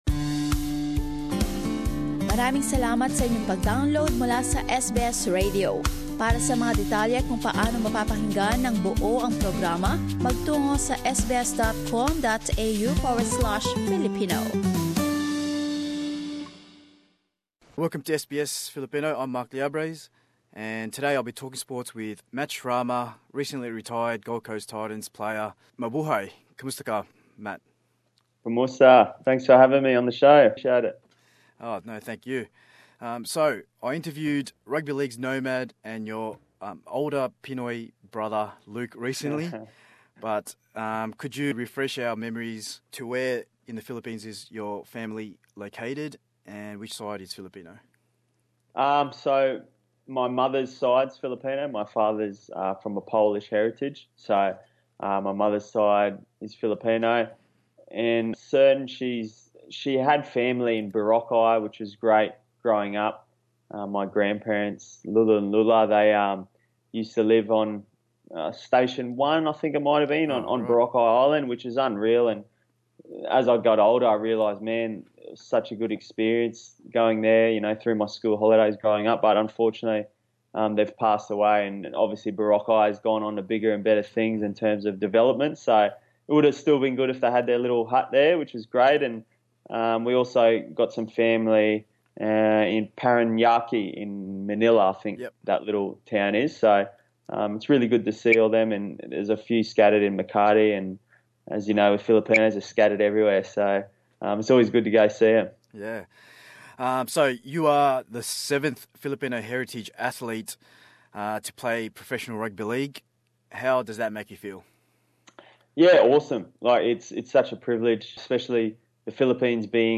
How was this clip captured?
being interviewed on Skype